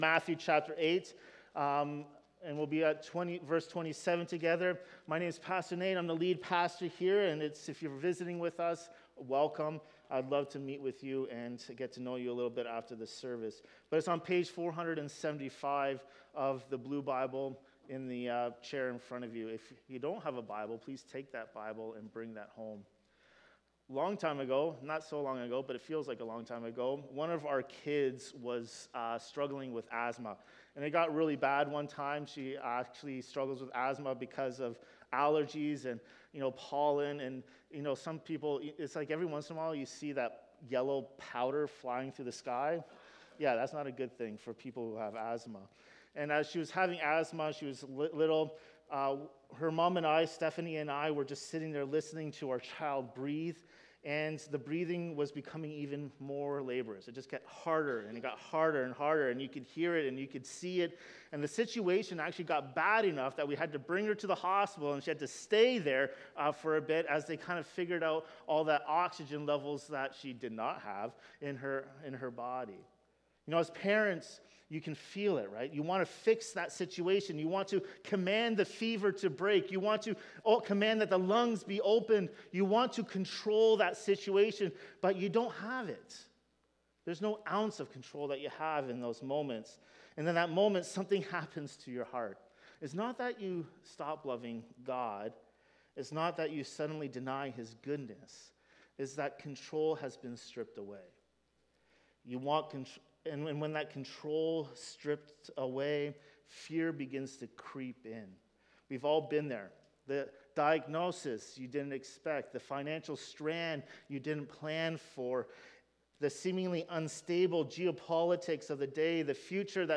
A sermon on Matthew 8:23–27 exploring Jesus calming the storm, the difference between fear and faith, and how we can rest in the storm because Christ reigns over it.